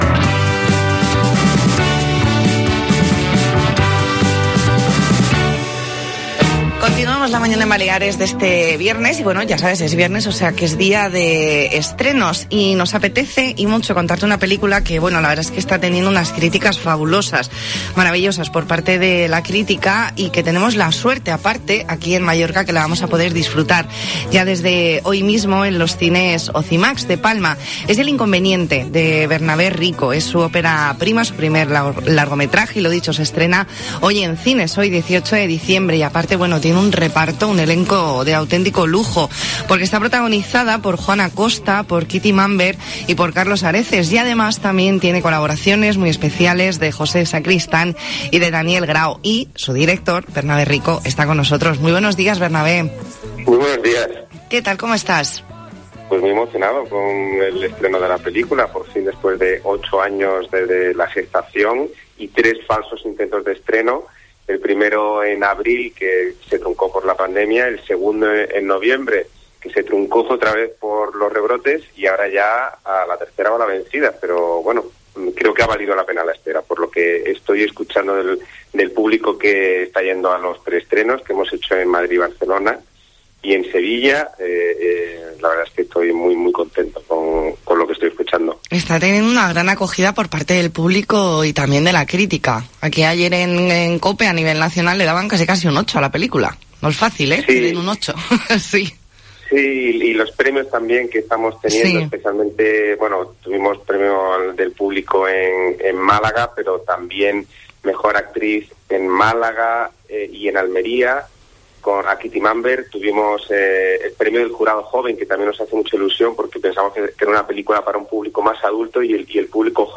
Entrevista en La Mañana en COPE Más Mallorca, viernes 18 de diciembre de 2020.